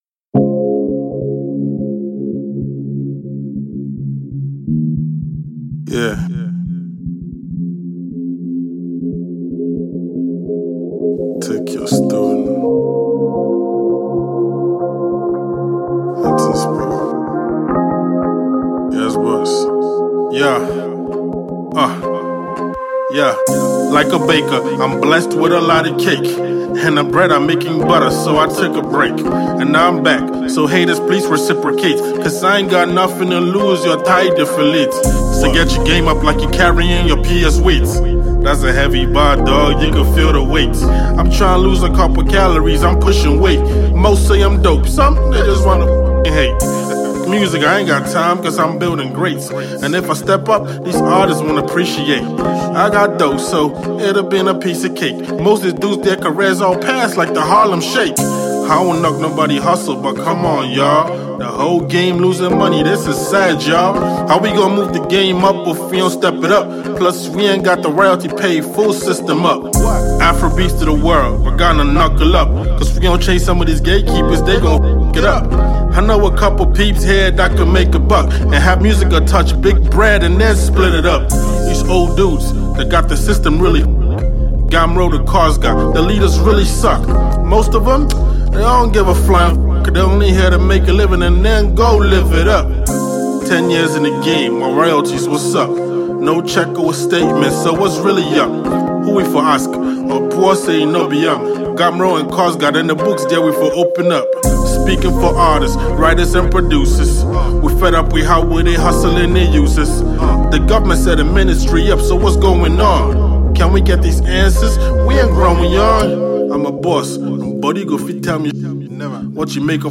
Hip-Hop banger